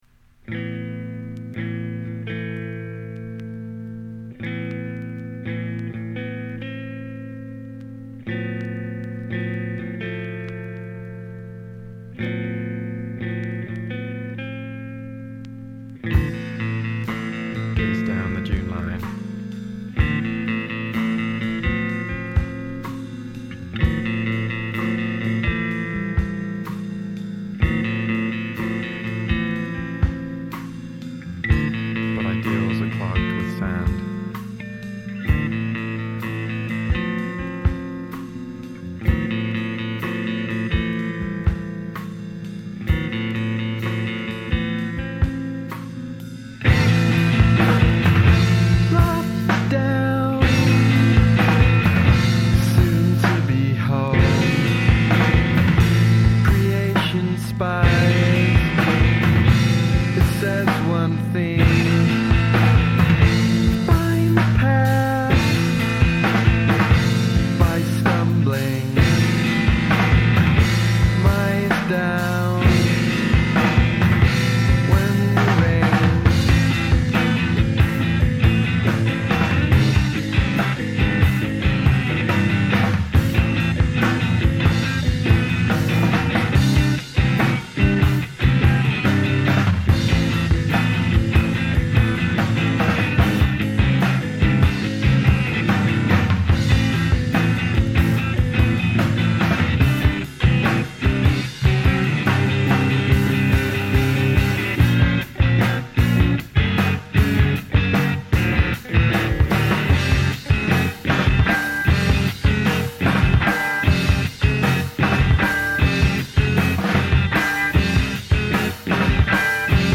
Melancholisch, jazzy, ik dig.